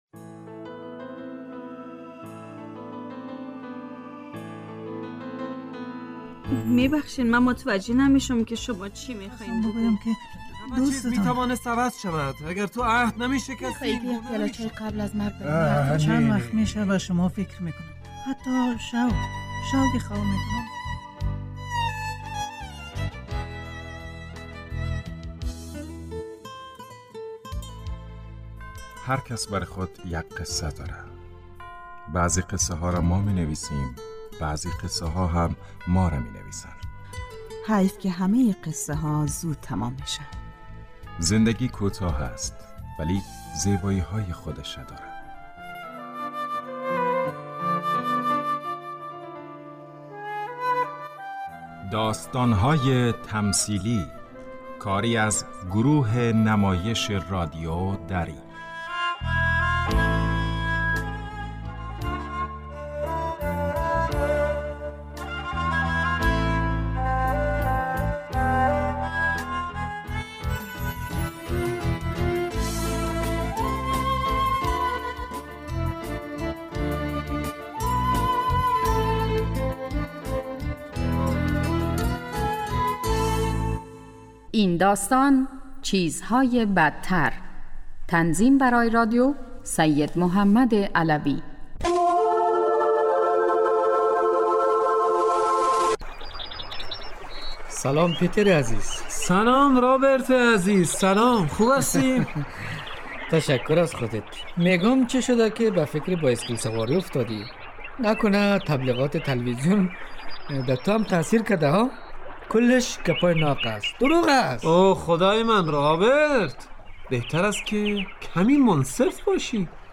داستان تمثیلی / چیزهای بدتر